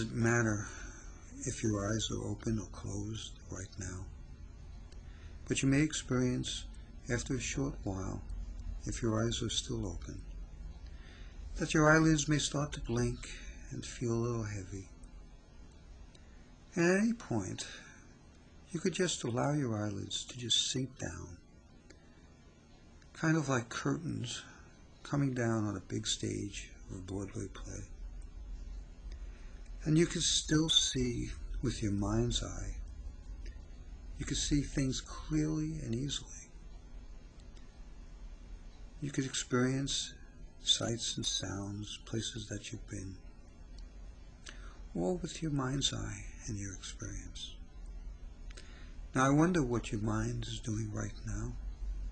Relaxation CD